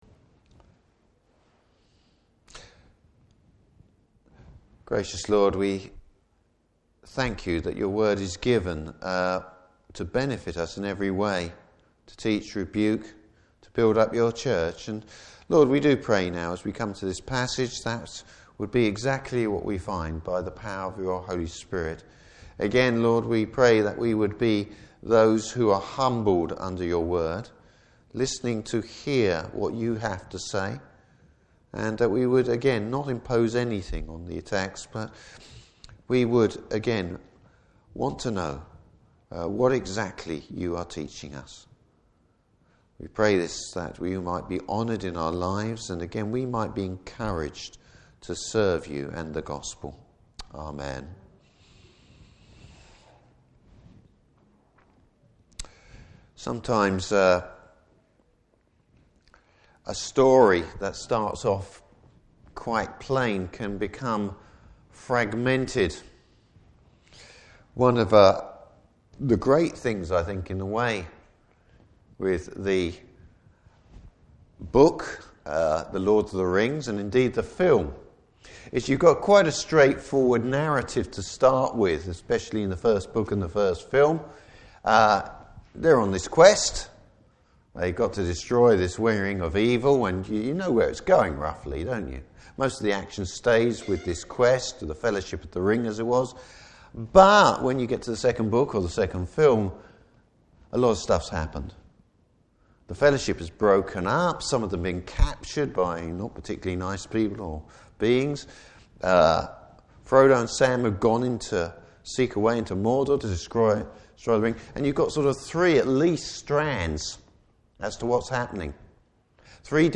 Service Type: Morning Service Bible Text: 2 Thessalonians 2:1-12.